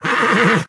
Horse Whinny
horse-whinny-4.ogg